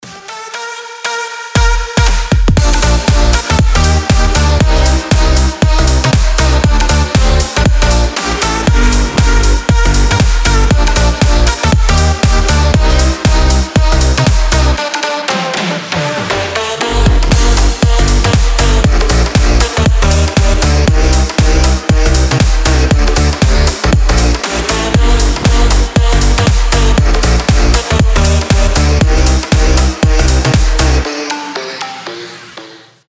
• Качество: 320, Stereo
громкие
мощные
EDM
future house
взрывные
энергичные
Громкий фьюче-хаус на звонок телефона